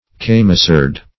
camisard - definition of camisard - synonyms, pronunciation, spelling from Free Dictionary
Search Result for " camisard" : The Collaborative International Dictionary of English v.0.48: Camisard \Cam"i*sard\, n. [F.]